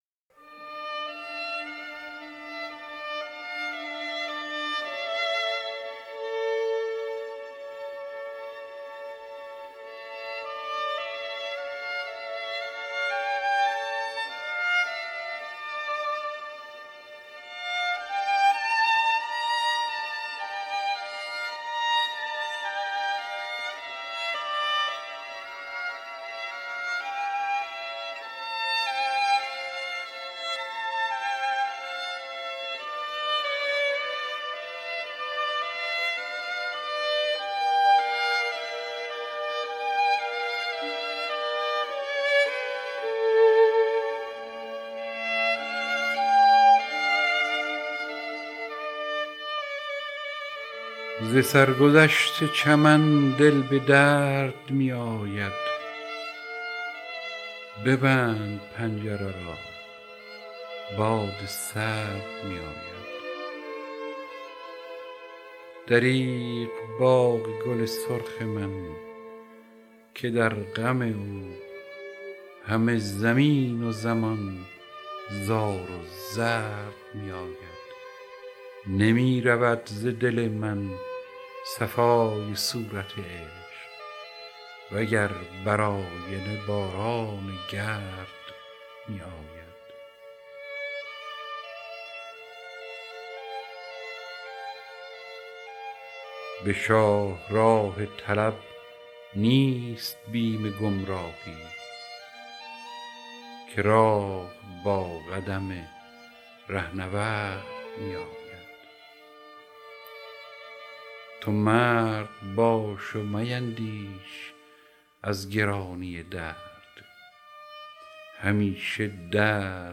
دانلود دکلمه سرگذشت چمن با صدای هوشنگ ابتهاج با متن دکلمه
گوینده :   [هوشنگ ابتهاج]